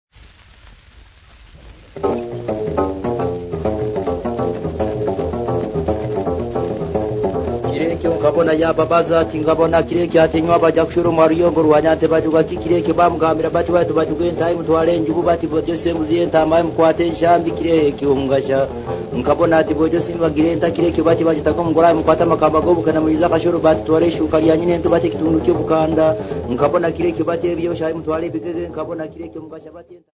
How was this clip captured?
Field recordings Africa Tanzania Bukoba f-sa